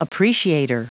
Prononciation du mot appreciator en anglais (fichier audio)